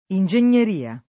ingegneria [ in J en’n’er & a ] s. f.